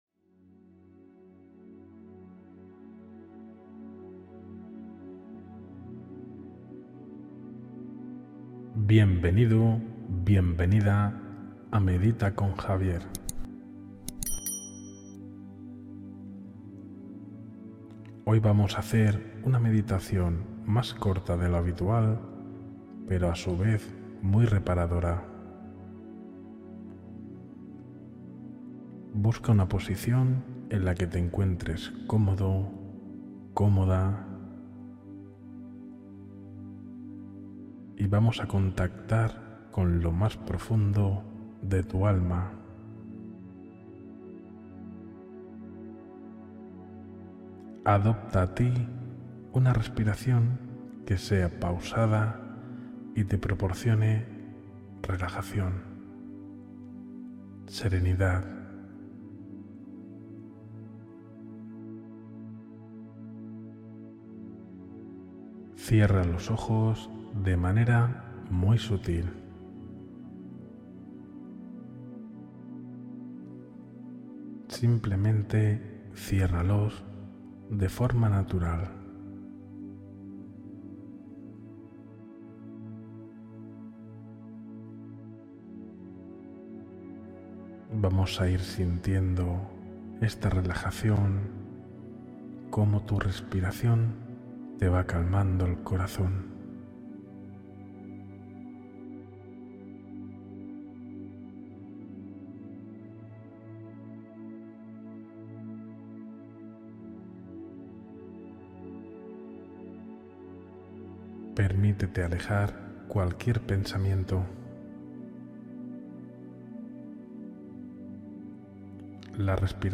Meditación Guiada para Conectar con tu Yo Superior en Calma